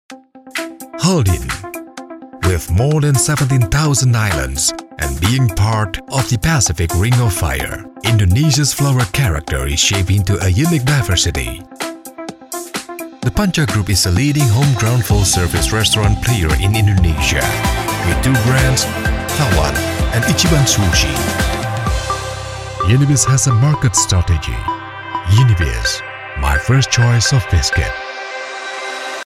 WARM VOICE & DRAMATIC
Sprechprobe: Sonstiges (Muttersprache):